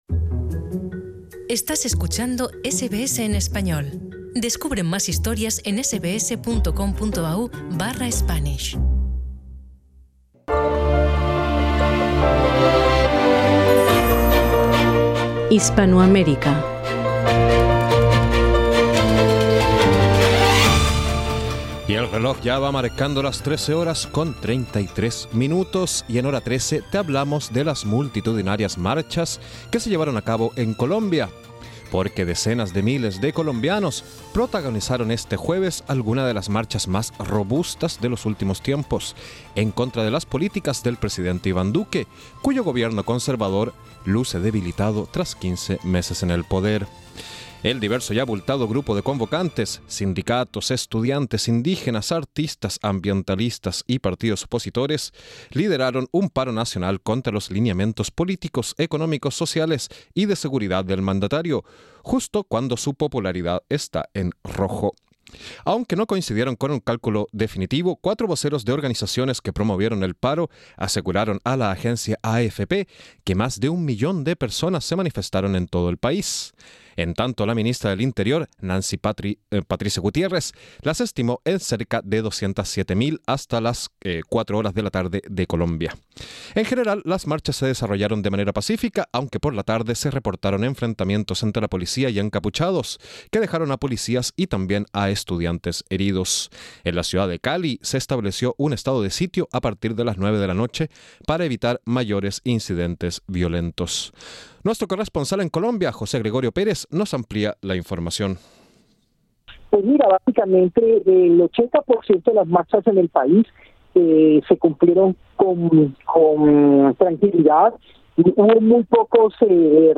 Nuestro corresponsal en Colombia